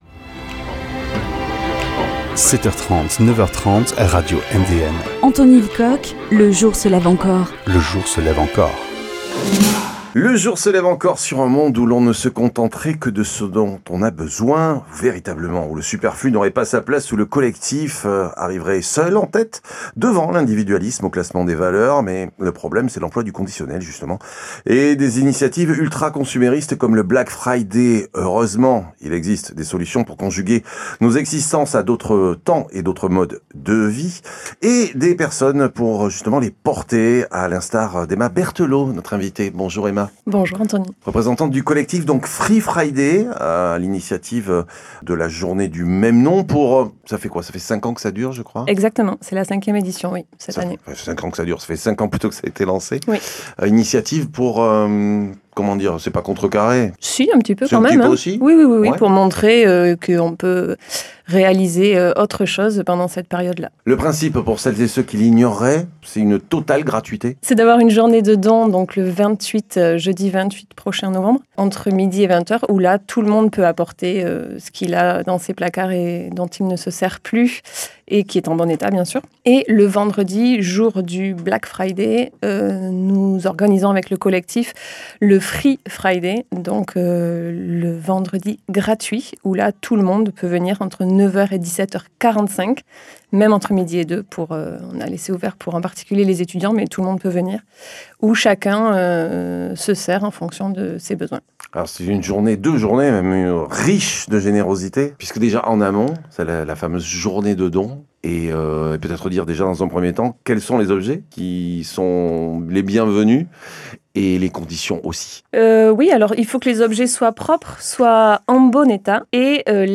Échanges